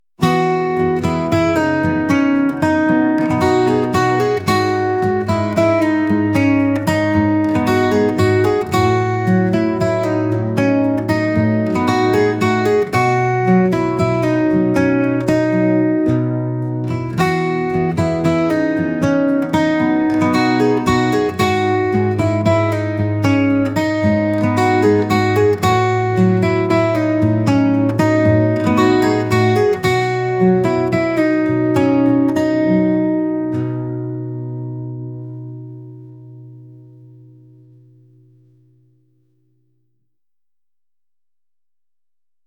indie | acoustic